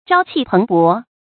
朝氣蓬勃 注音： ㄓㄠ ㄑㄧˋ ㄆㄥˊ ㄅㄛˊ 讀音讀法： 意思解釋： 形容生氣勃勃；充滿活力（朝：早上；蓬勃：旺盛的樣子）。